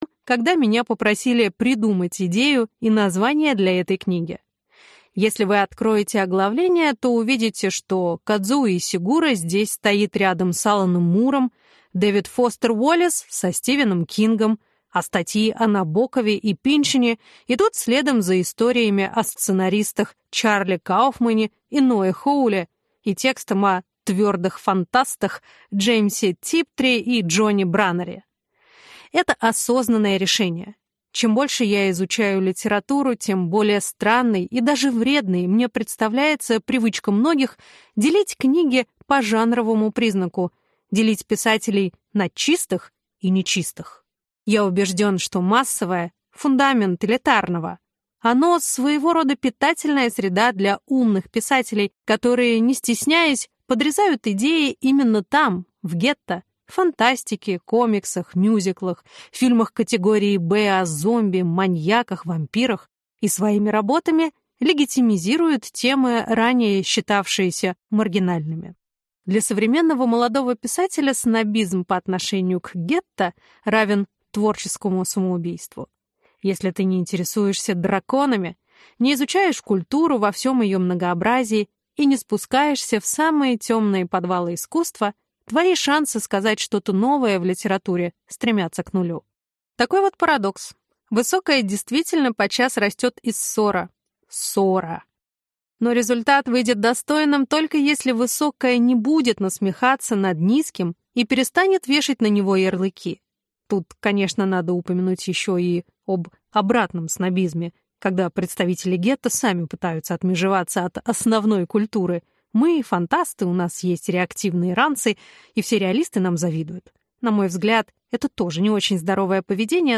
Аудиокнига Почти два килограмма слов | Библиотека аудиокниг